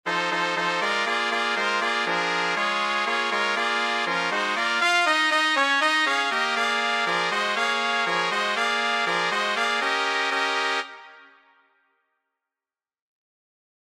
Key written in: B♭ Major
How many parts: 4
Type: Female Barbershop (incl. SAI, HI, etc)
All Parts mix: